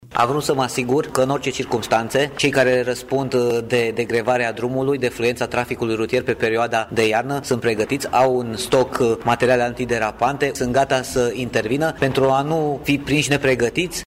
Ciprian Băncilă, prefectul județului Brașov: